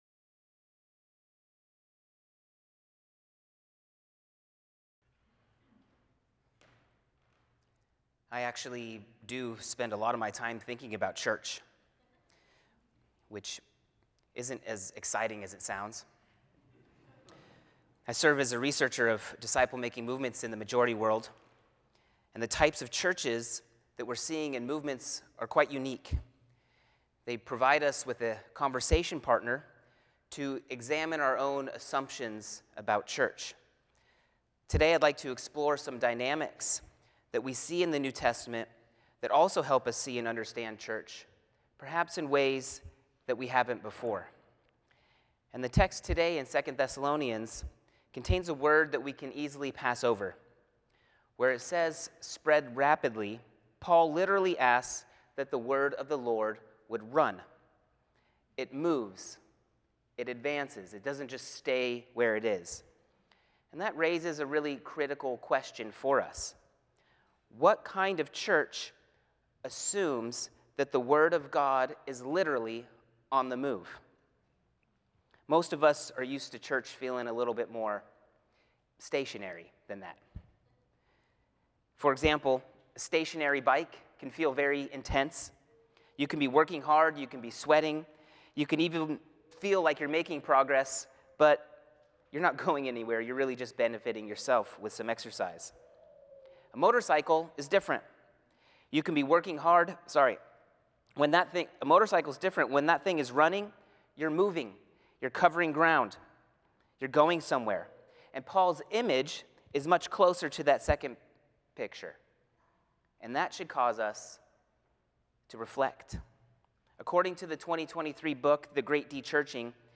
The following service took place on Thursday, April 9, 2026.